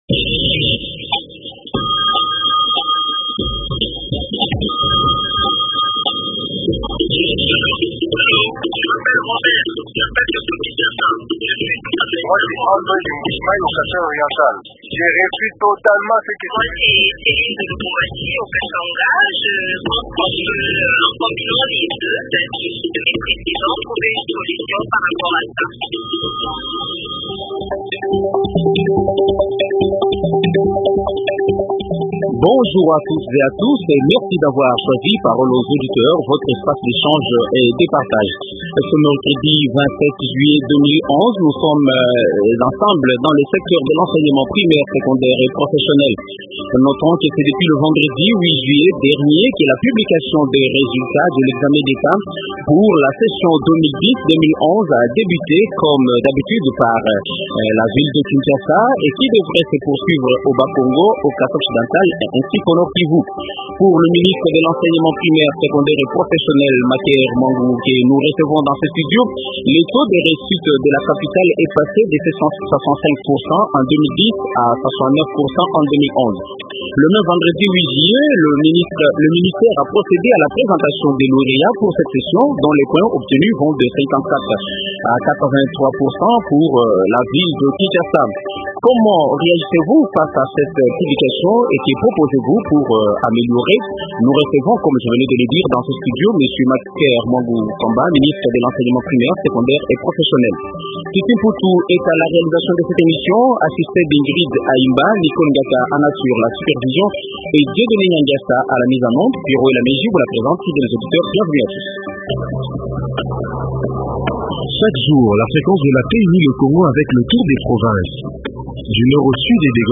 Invité: Maker Muangu Famba, ministre de l’enseignement primaire, secondaire et professionnel.